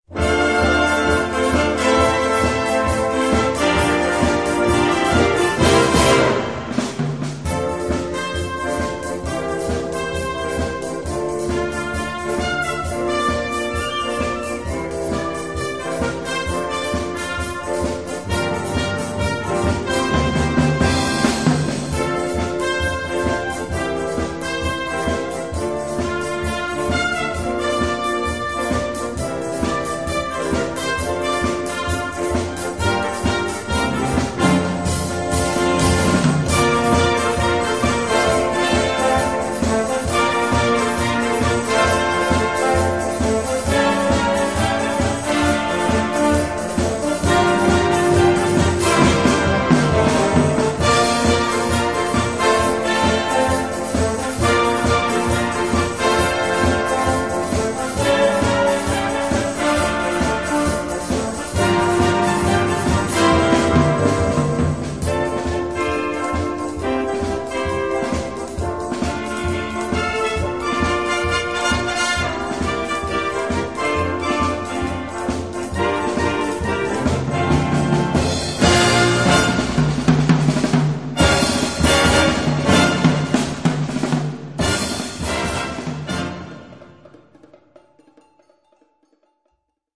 Gattung: Moderne Blasmusik
2:27 Minuten Besetzung: Blasorchester Zu hören auf